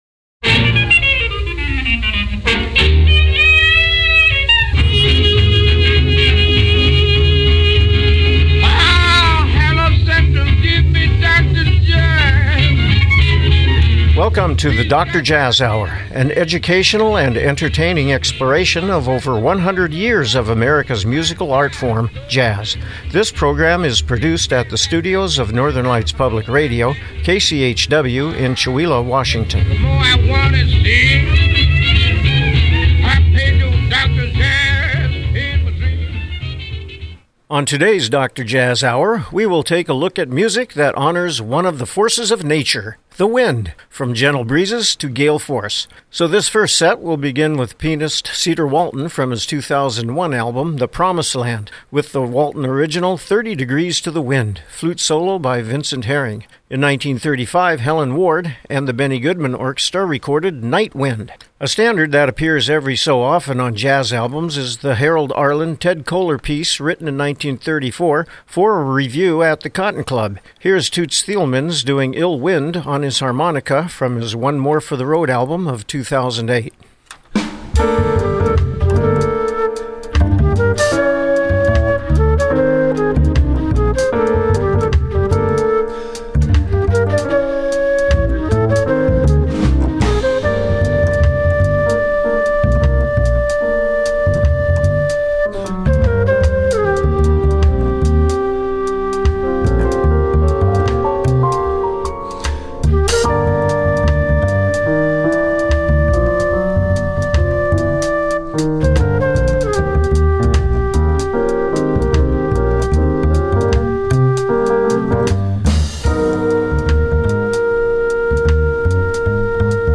Program Type: Music